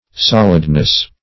Solidness \Sol"id*ness\, n.